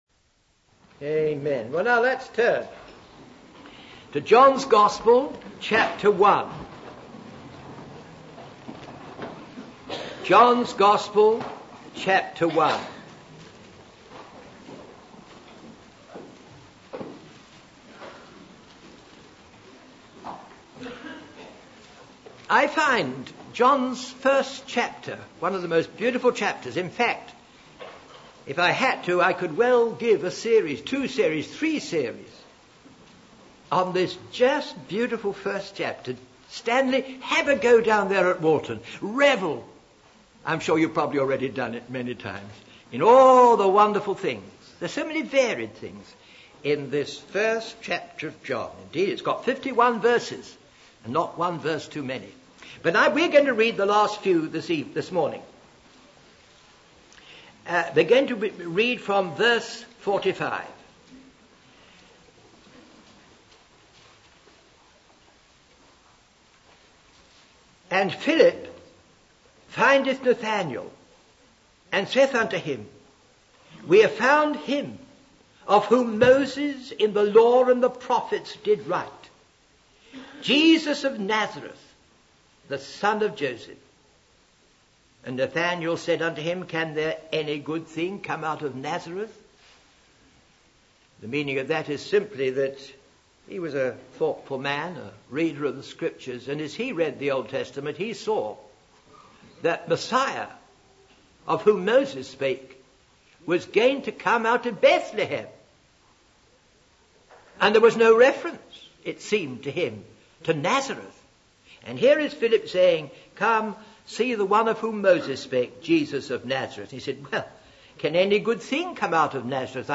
In this sermon, the preacher emphasizes the importance of having faith and trust in God. He uses the metaphor of a ladder leading to heaven to illustrate the idea that even when life feels difficult, there is always a way to connect with God.